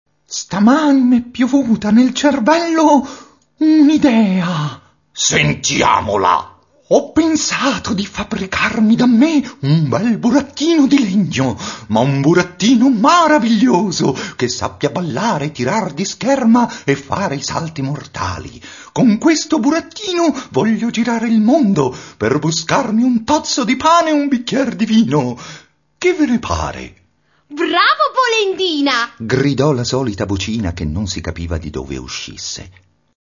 Inoltre è possibile ascoltare il racconto interamente recitato e narrato da attori professionisti.